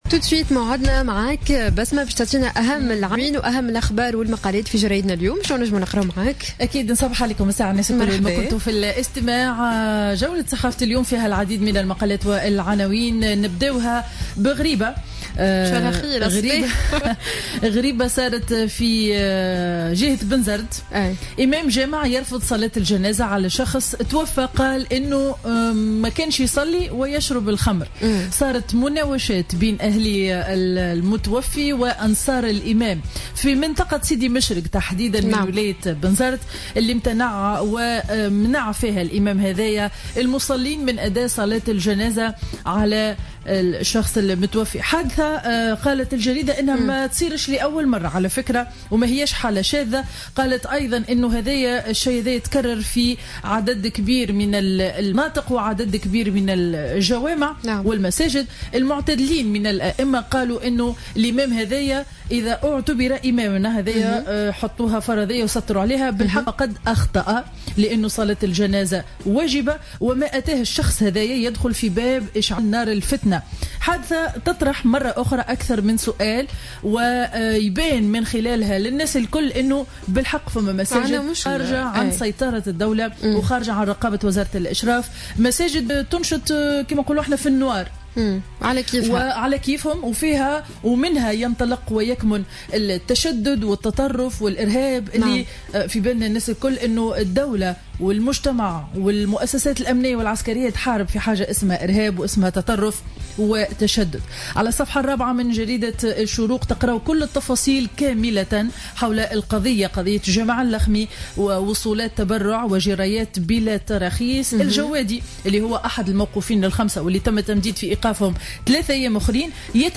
معرض الصحافة ليوم الأربعاء 28 أكتوبر 2015